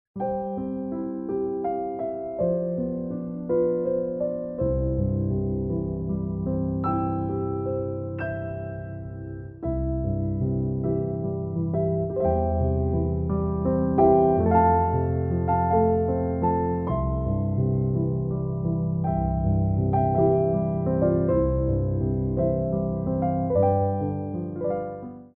12/8 (6x8)